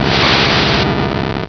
Cri d'Alakazam dans Pokémon Rubis et Saphir.